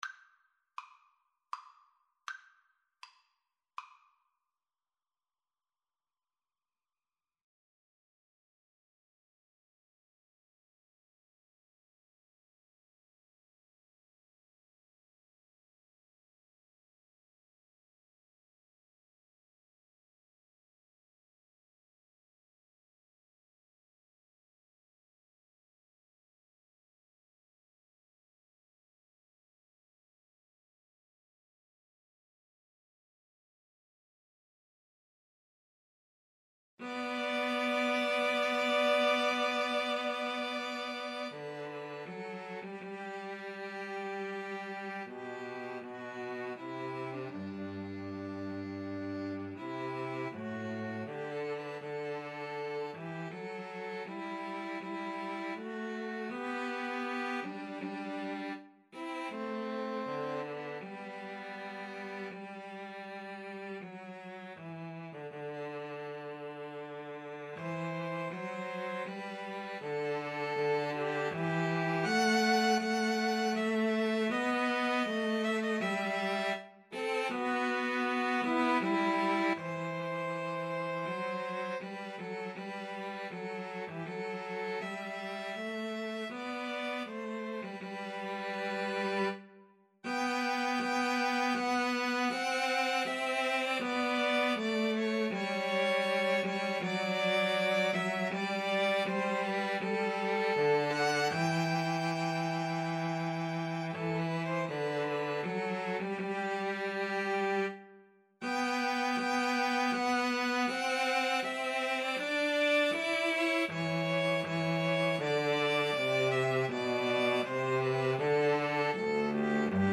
Free Sheet music for String trio
G major (Sounding Pitch) (View more G major Music for String trio )
Andante grandioso